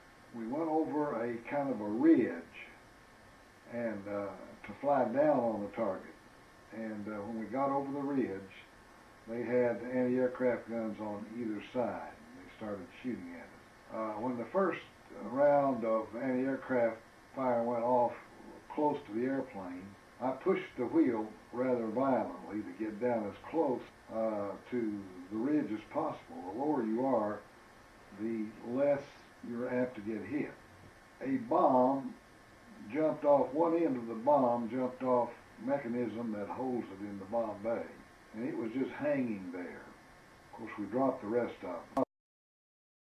and interview with his father.